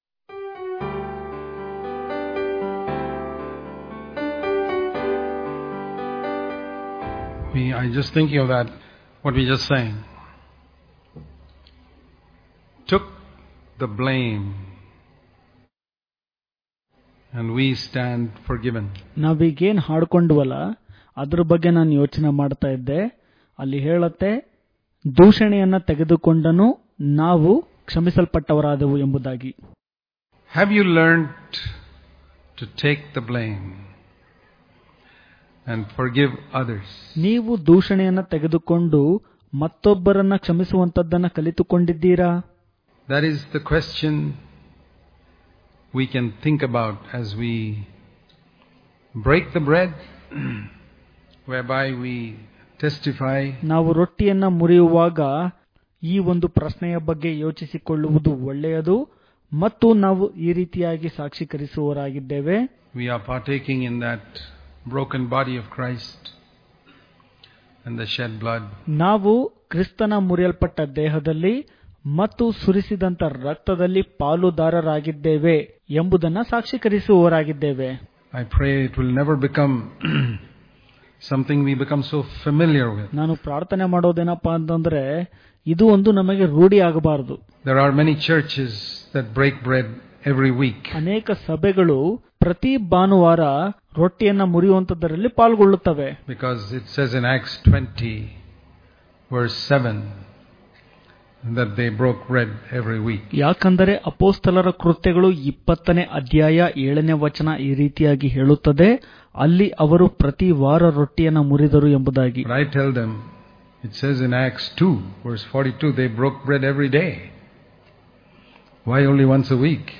July 25 | Kannada Daily Devotion | Seeing The Cross A Fresh Every Day Daily Devotions